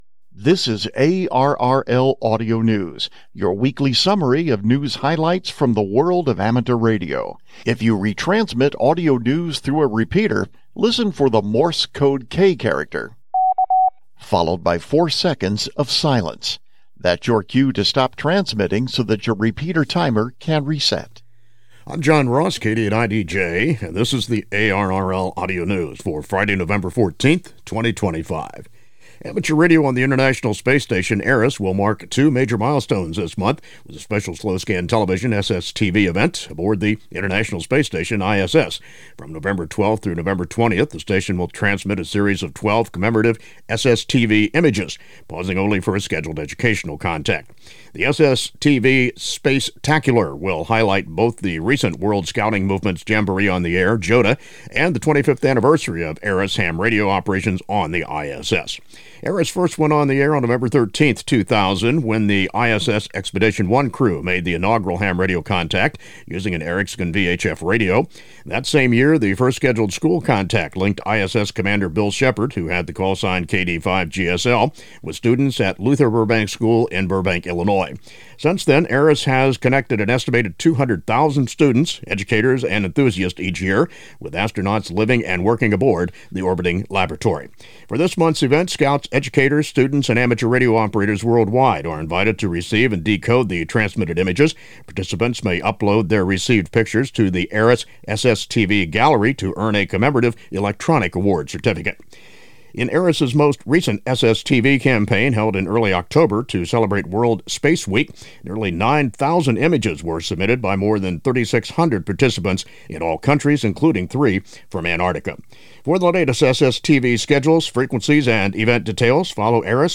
ARRL Audio News is a summary of the week’s top news stories in the world of amateur radio, along with interviews and other features.